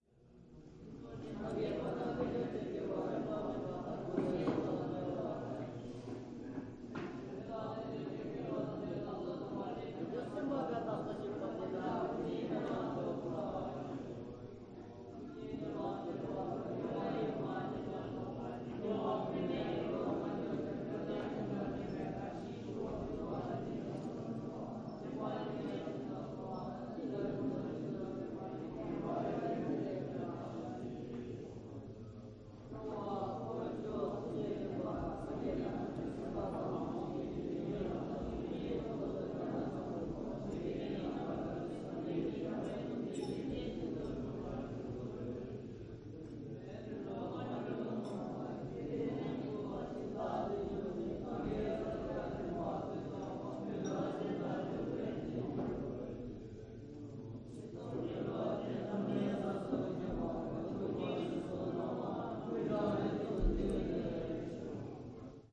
寺庙内的诵经声
描述：记录在韩国天安市的佛教寺庙Gakwonsa寺。僧侣们高呼，录音是在寺庙内进行的。
标签： 西藏 寺庙 朝鲜 天安 诵经 南韩国 僧侣 僧侣 佛教 口头禅
声道立体声